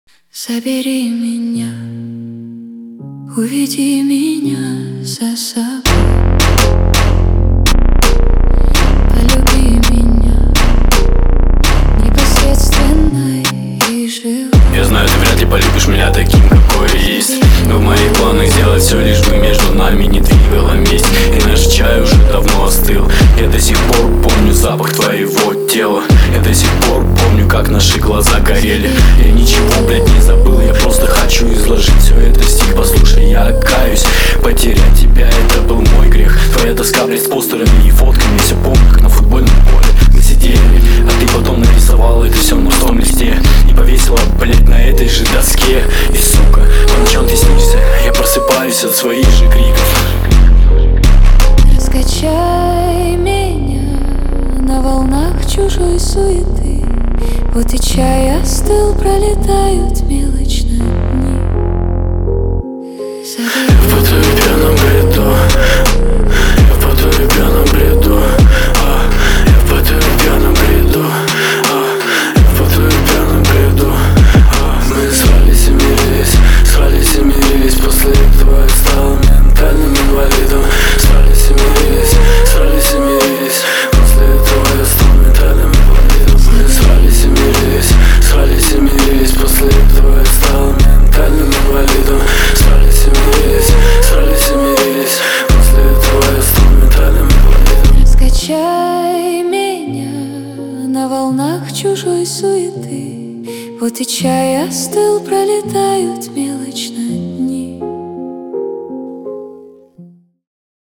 Трек размещён в разделе Рэп и хип-хоп / Русские песни.